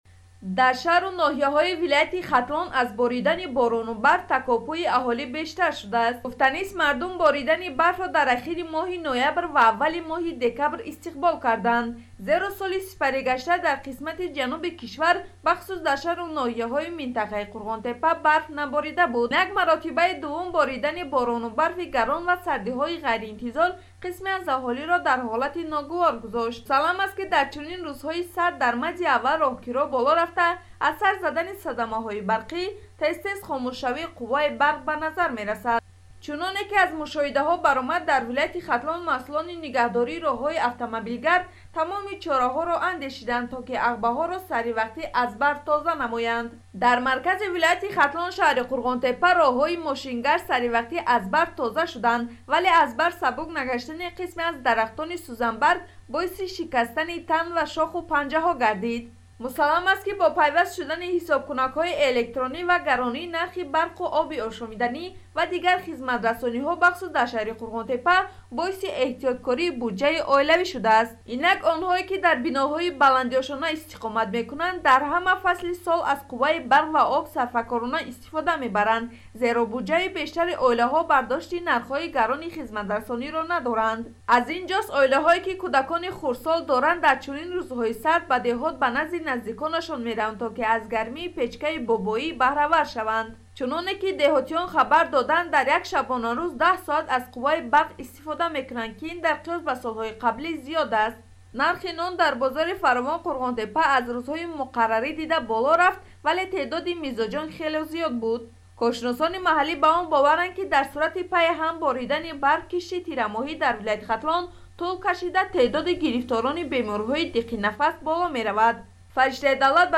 гузориш медиҳад